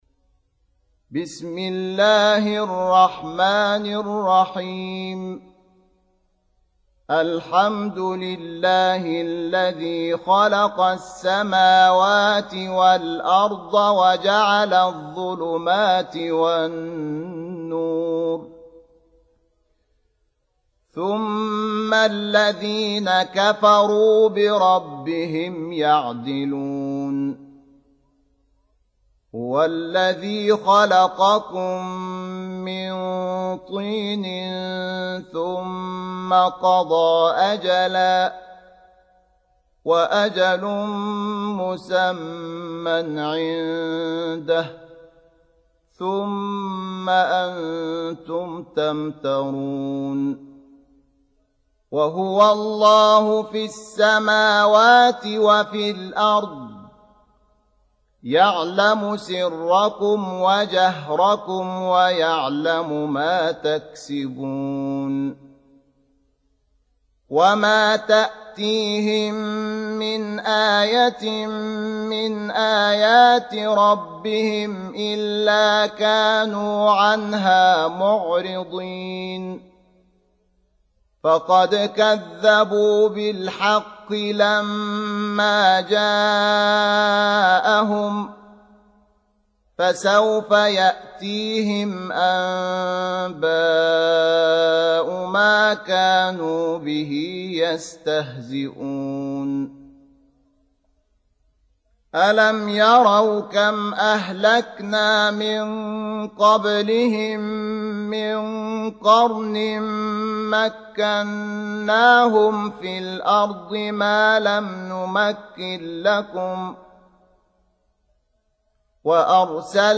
6. Surah Al-An'�m سورة الأنعام Audio Quran Tarteel Recitation
Surah Repeating تكرار السورة Download Surah حمّل السورة Reciting Murattalah Audio for 6.